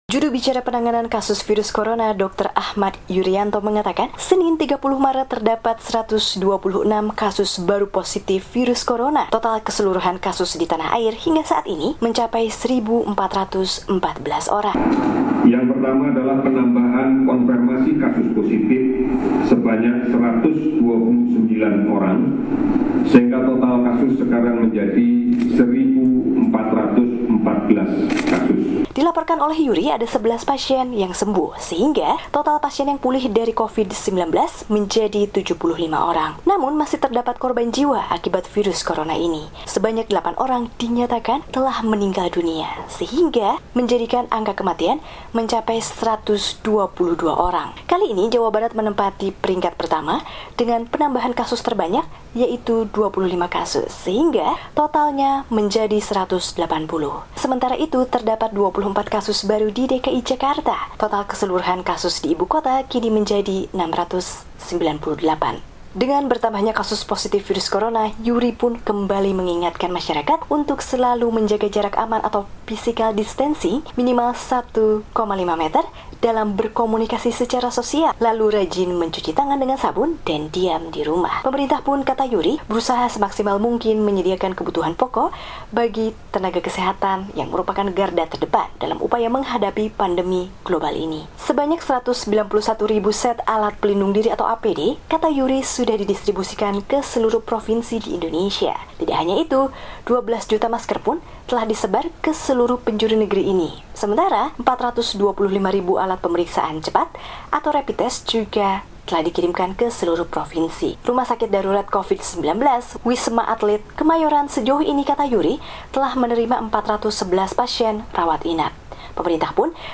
Saya mengingatkan kembali untuk jaga jarak,” ungkapnya dalam telekonferensi di gedung BNPB, Jakarta, Senin (30/3).